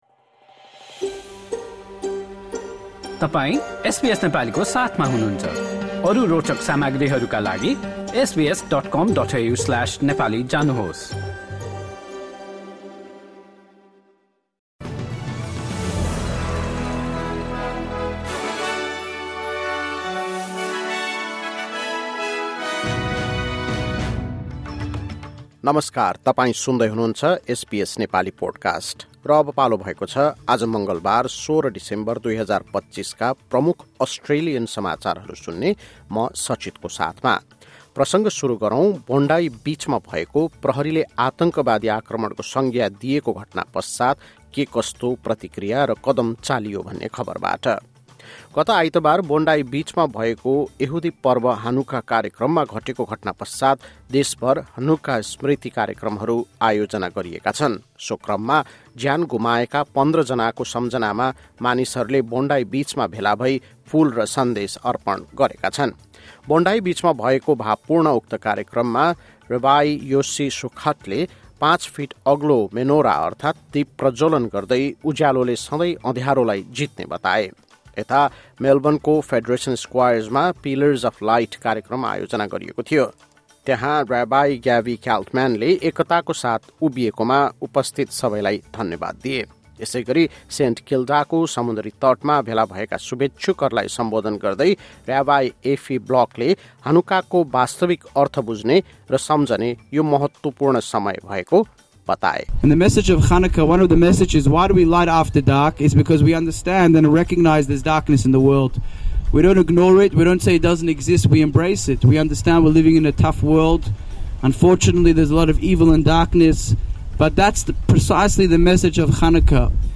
एसबीएस नेपाली प्रमुख अस्ट्रेलियन समाचार: मङ्गलवार, १६ डिसेम्बर २०२५